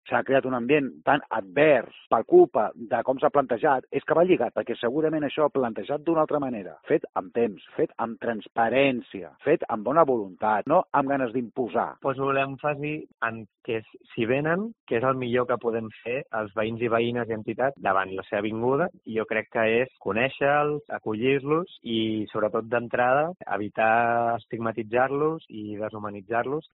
Dos vecinos de Sant Pere de Vilamajor opinan sobre la instalación de la residencia de inserción laboral